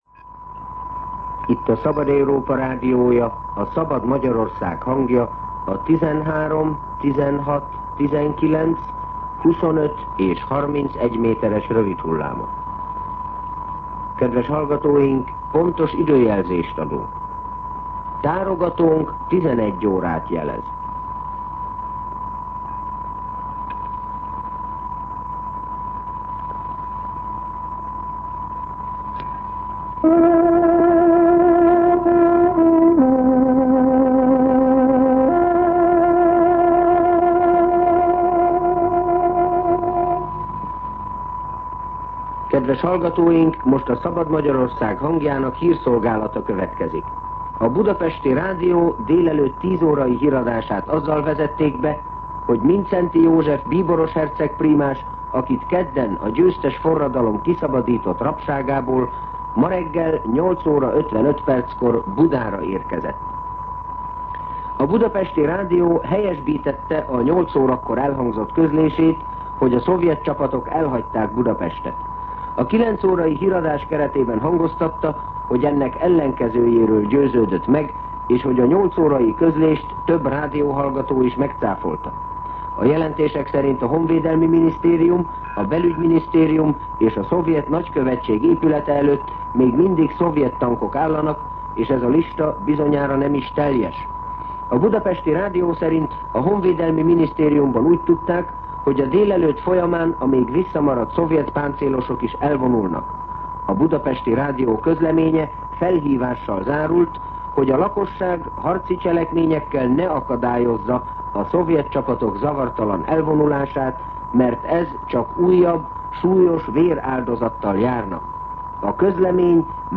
11:00 óra. Hírszolgálat